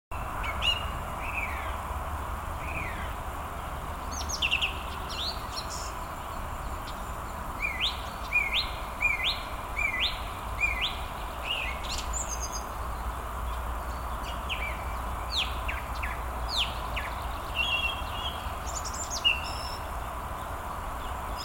певчий дрозд, Turdus philomelos
Administratīvā teritorijaBabītes novads
СтатусПоёт